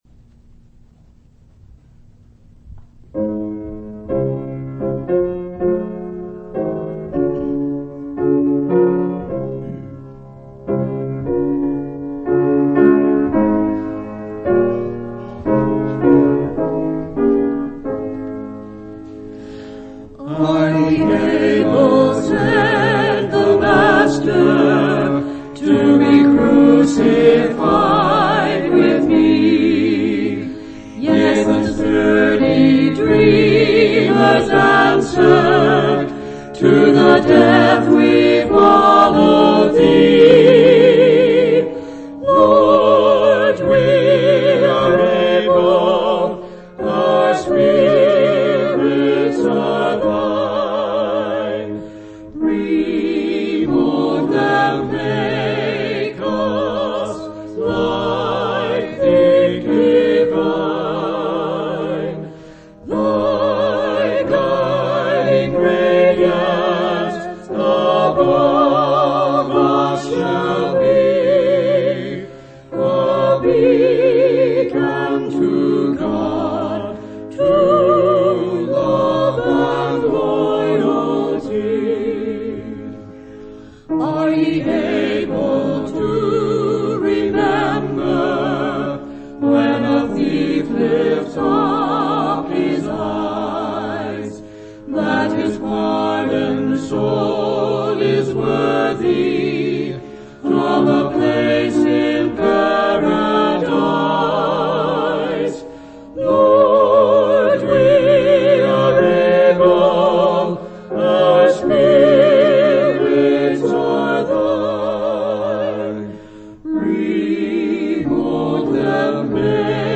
audio-sermons